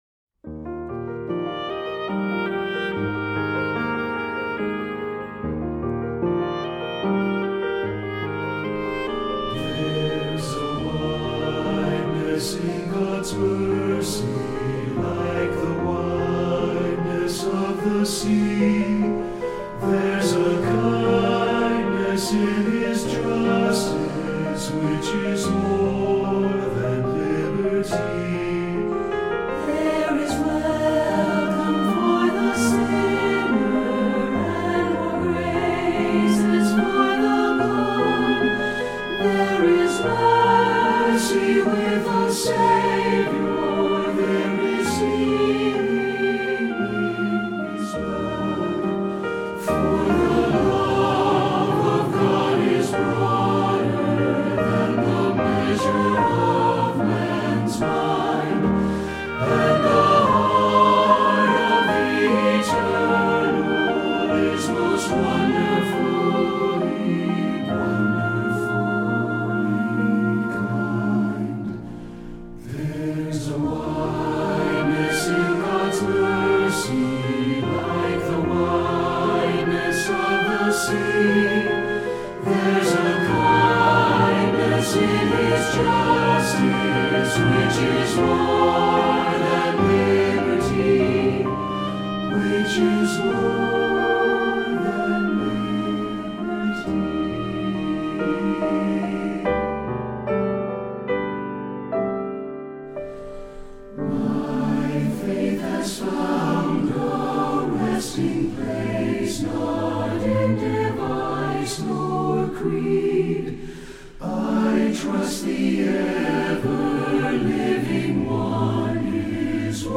Voicing: SATB and Oboe